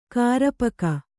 ♪ kārapaka